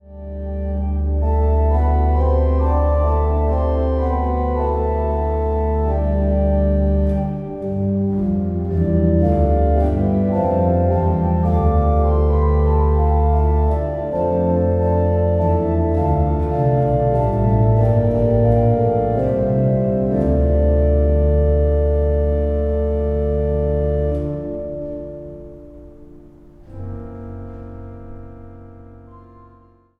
Christmas Carols
orgel
Zang | Jongenskoor